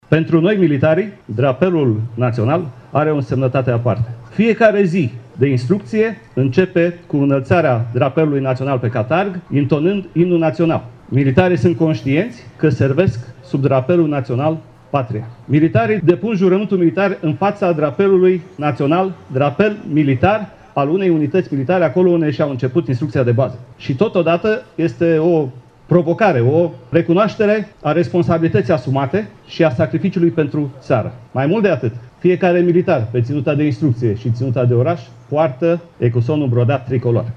La Iaşi, în Piaţa Palatului, s-a desfăşurat un ceremonial militar şi altul religios.